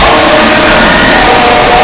OMG SUM BITS HELLO (loud) Download